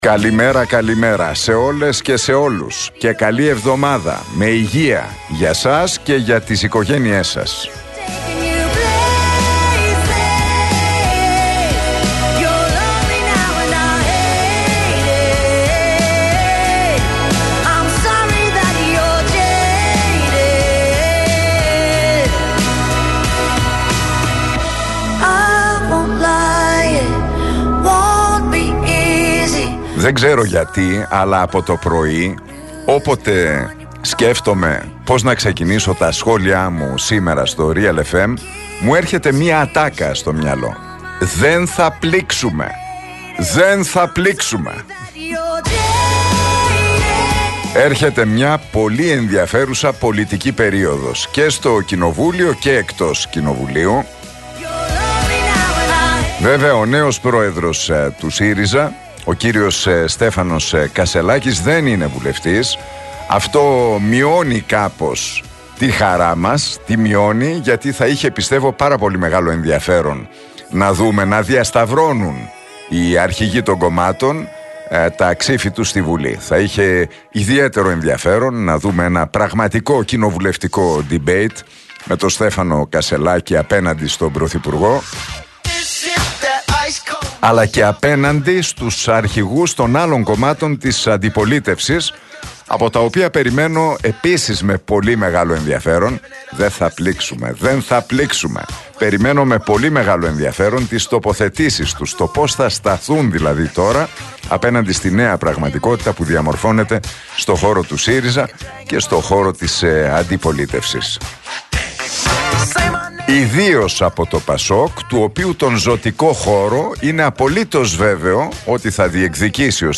Ακούστε το σχόλιο του Νίκου Χατζηνικολάου στον RealFm 97,8, την Δευτέρα 25 Σεπτεμβρίου 2023.